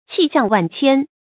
氣象萬千 注音： ㄑㄧˋ ㄒㄧㄤˋ ㄨㄢˋ ㄑㄧㄢ 讀音讀法： 意思解釋： 氣象：景象；萬千：泛指極多。